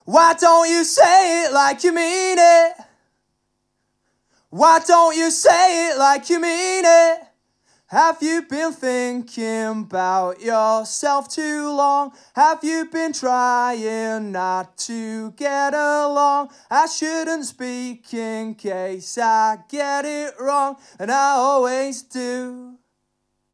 Échantillons sonores Audio Technica AE-2500
Audio Technica AE-2500 mikrofon - mêski wokal